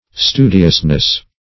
Stu"di*ous*ness, n.